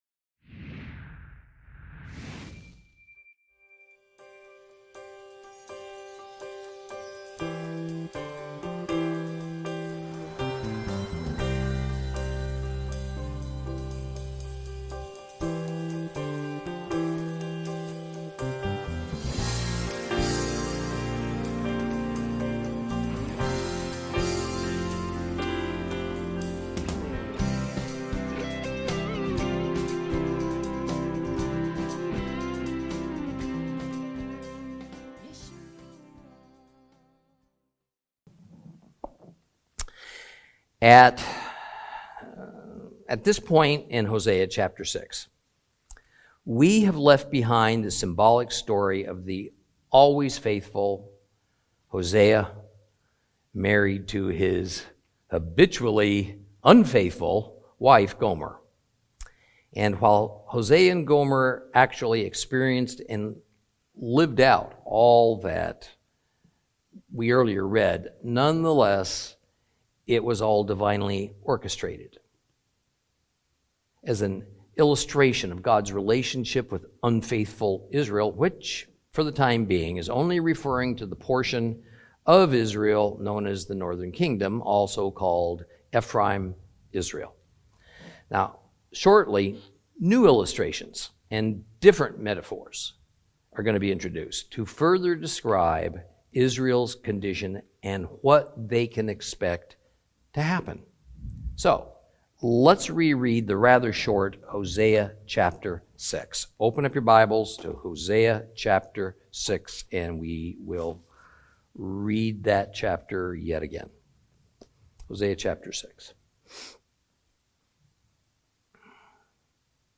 Teaching from the book of Hosea, Lesson 11 Chapters 6 and 7.